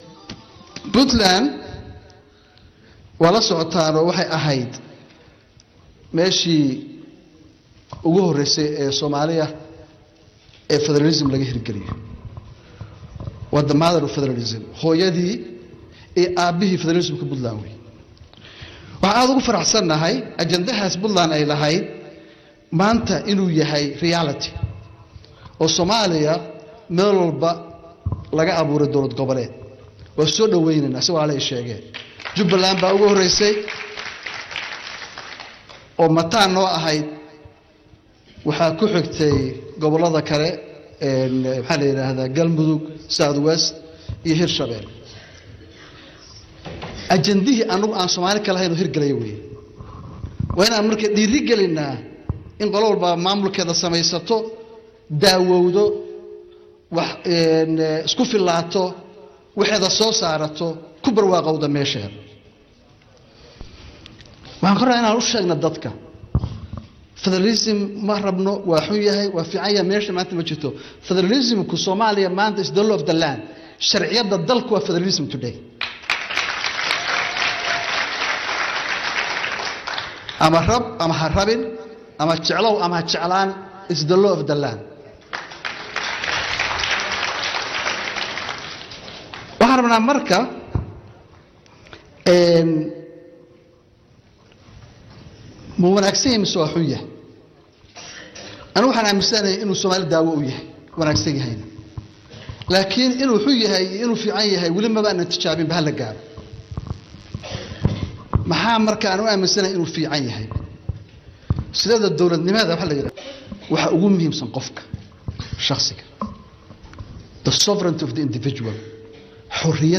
13 May 2017 (Puntlandes) Madaxweynaha dawladda Puntland Dr Cabdiweli Maxamed Cali Gaas oo ka hadlayey Xaflad soo dhawayn ah oo ay Jaaliyadda Puntland ee magaalada London ee dalka UK u samaysay wafdi uu hogaaminayey madaxweynaha, oo London u yimid ka qaybgalka shirkii Soomaalida ee London ka dhacay ayaa ka hadlay inay Soomaaliya ay qaadatay Fadaraalnimo cidii jecela ama jeclayna ay tahay inay ku shaqayso taas.
Dhagayso madaxweyne Dr Gaas oo ka hadlaya inay Soomaaliya qaadatay Fadaraal